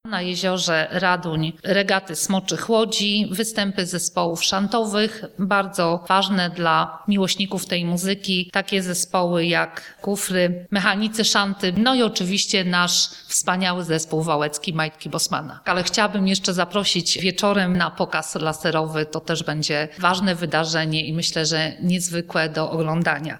Zabawa rozpoczęła się już o godzinie 11:00 regatami żeglarskimi na Jeziorze Zamkowym, atrakcji jest jednak – jak informuje wiceburmistrz  Joanna Rychlik-Łukasiewicz – znacznie więcej.